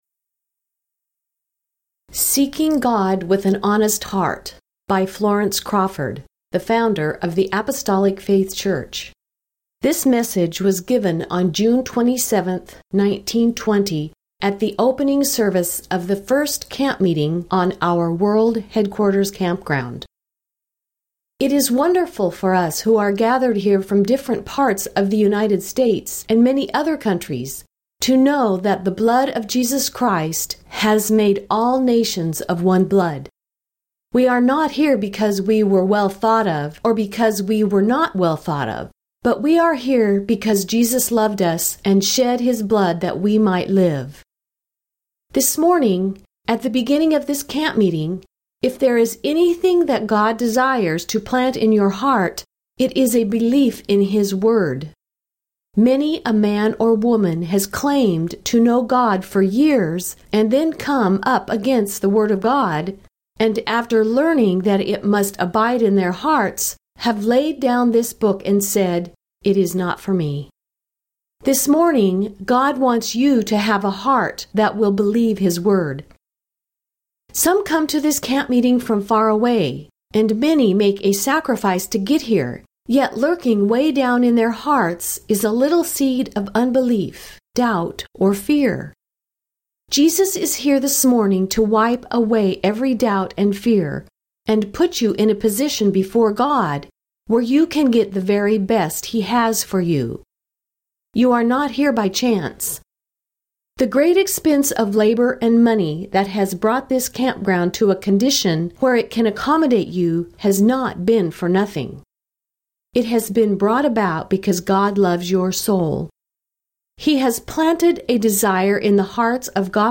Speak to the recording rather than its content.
Our Classics | The 1920 sermon that opened the first camp meeting on our world headquarters campground still rings true today.